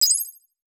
Start Game.wav